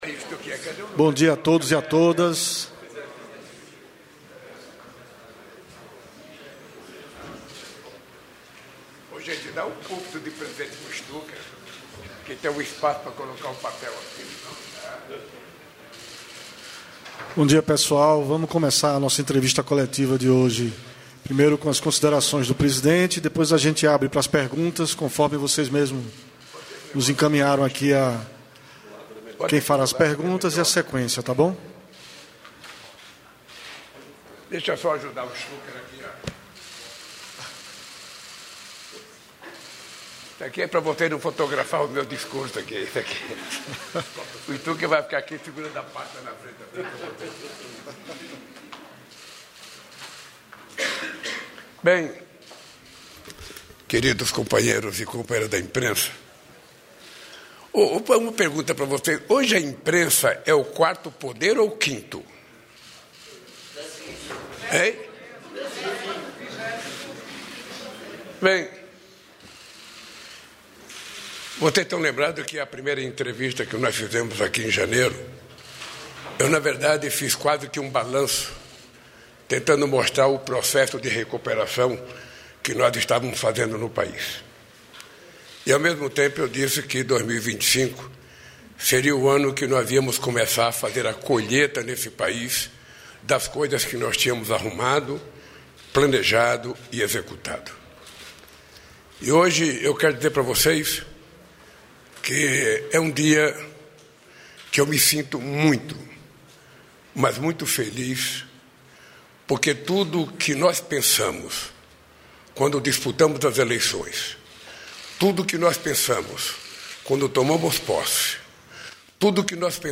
Íntegra das palavras do presidente da República Luiz Inácio Lula da Silva na cerimônia de inauguração do Hospital Universitário do Ceará (HUC), em Fortaleza, nesta quarta-feira (19). Unidade prestará atendimento a casos de alta complexidade, além de dar suporte a outros hospitais públicos da Região.